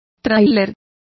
Complete with pronunciation of the translation of trailer.